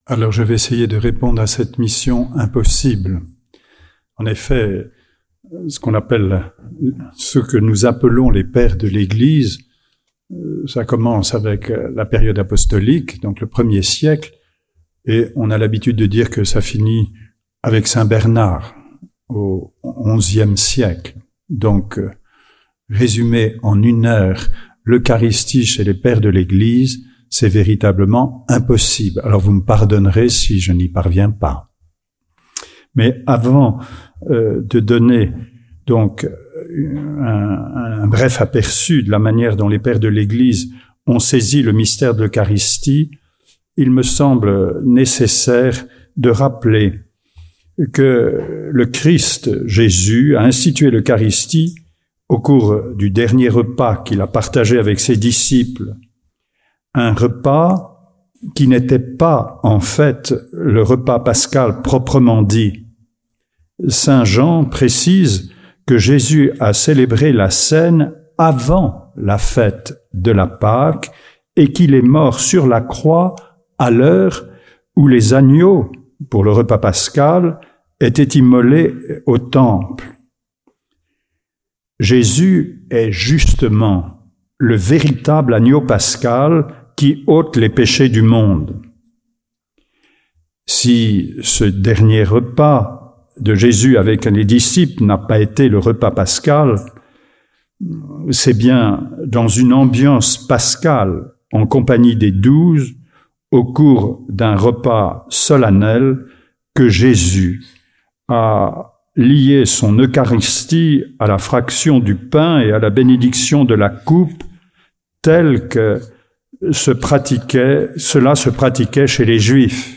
Toulon - Adoratio 2024 - Mgr Jean Legrez